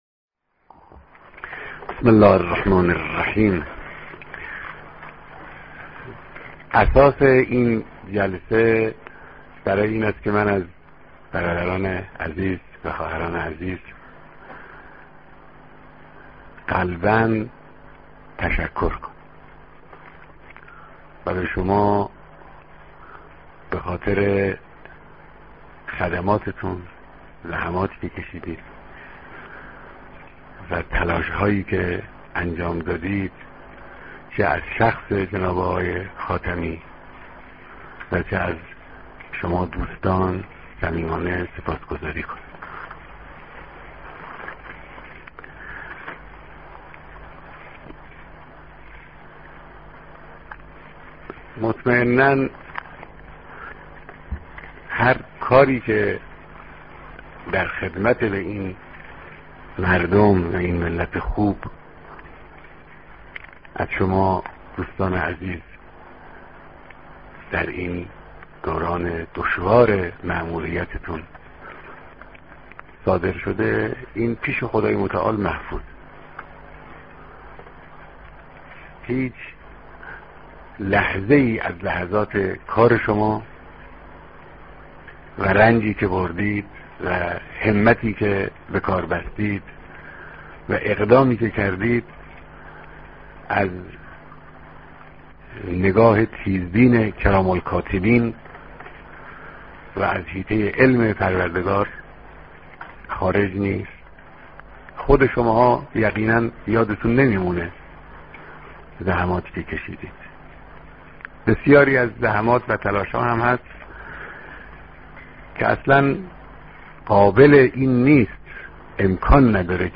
صوت کامل بیانات